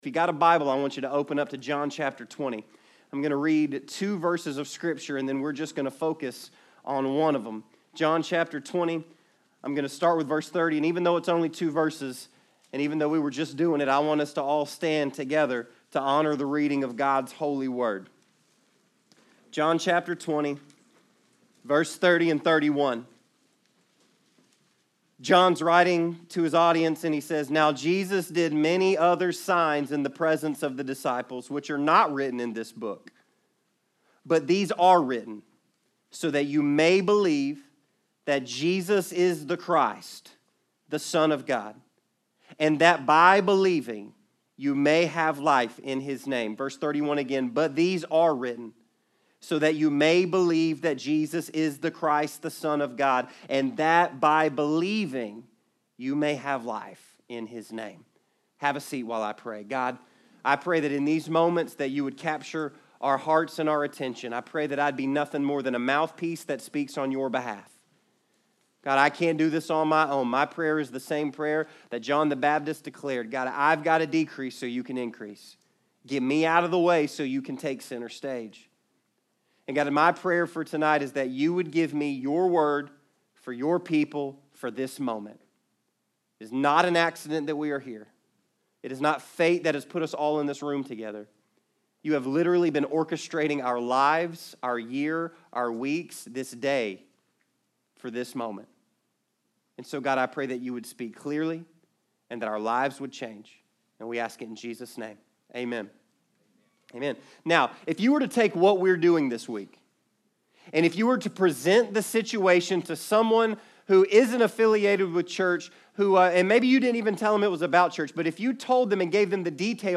Pulpit Guest Message